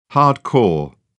Hardcore.wav